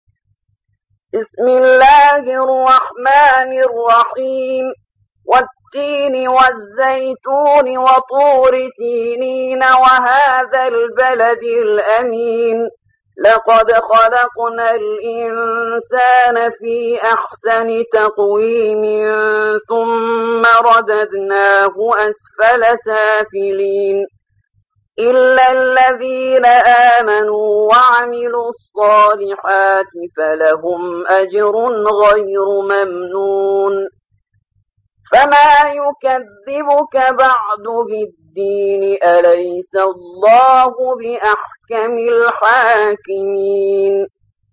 95. سورة التين / القارئ